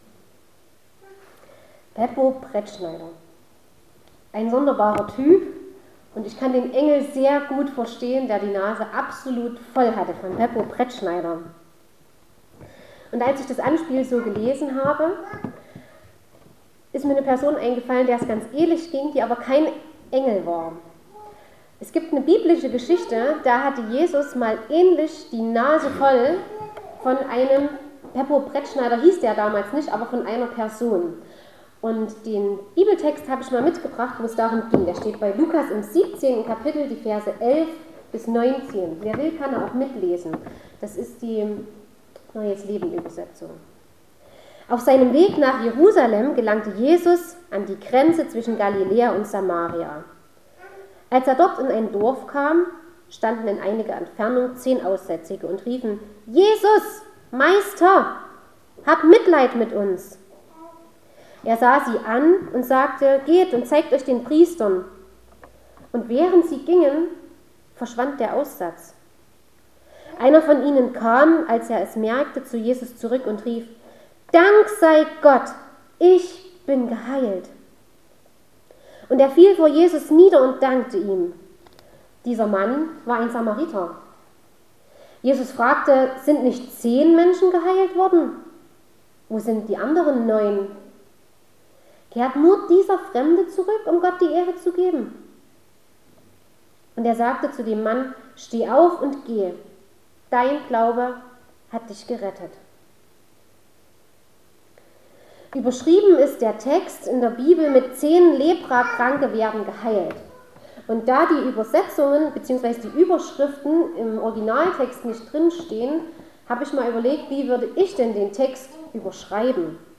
Eine Gastpredigt